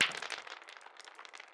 弹珠游戏音效
hit.ogg